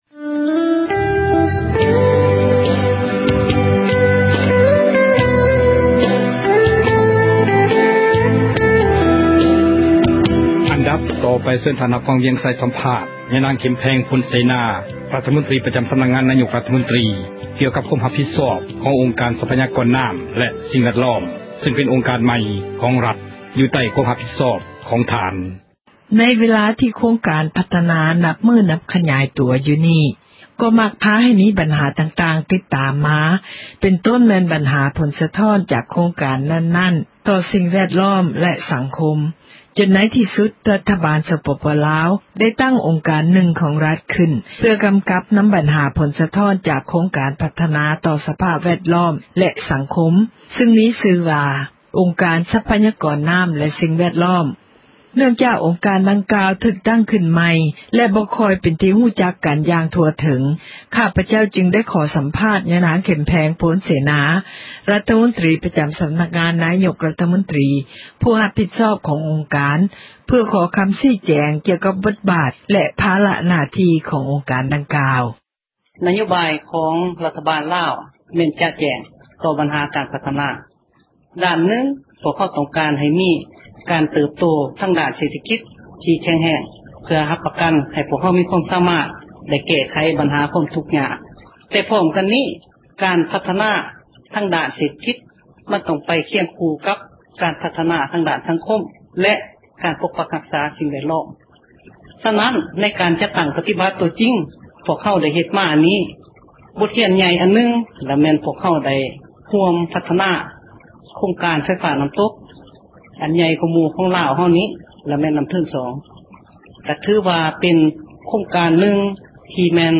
ສັມພາດຍານາງ ເຂັມແພງພົນເສນາ
ຍານາງເຂັມແພງ ພົນເສນາ ຣັຖມົນຕຣີປະຈຳ ສຳນັກງານ ນາຍົກຣັຖມົນຕຣີ ກໍາລັງໃຫ້ສັມພາດ ຜູ້ສື່ຂ່າວເອເຊັຽເສຣີ